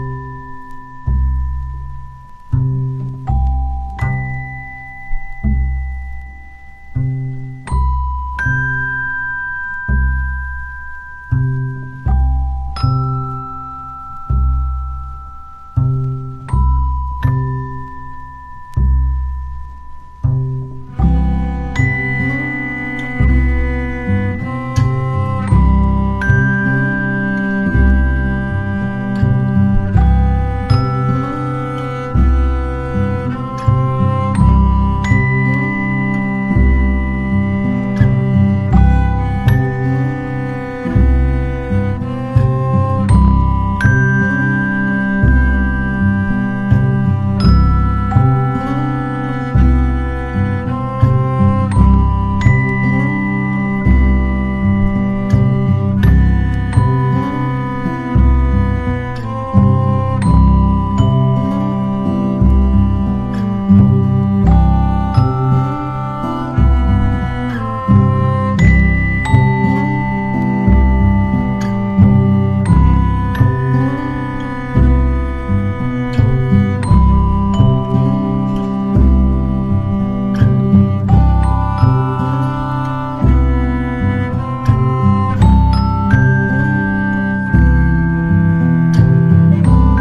極上のチルアウト・アンビエント・ミュージック！
AMBIENT / EXPERIMENTAL